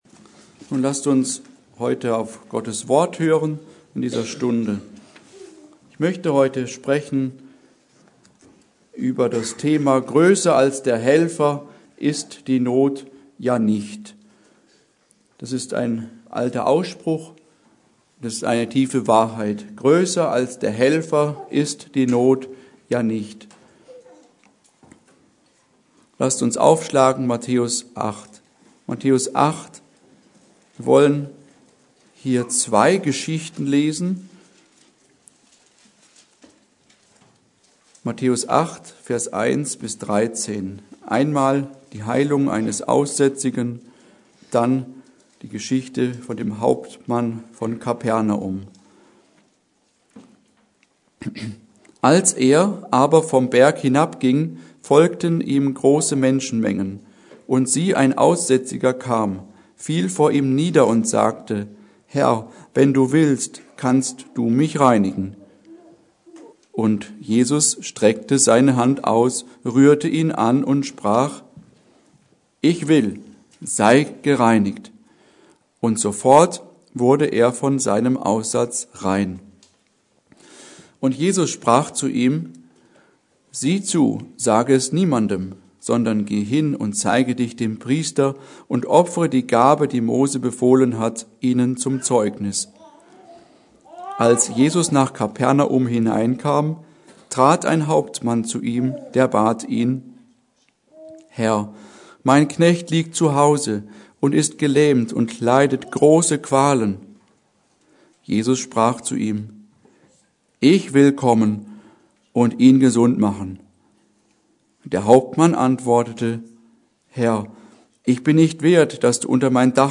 Serie: Gottesdienste Wegbereiter-Missionsgemeinde Passage: Matthäus 8,1-13 Kategorie: Predigten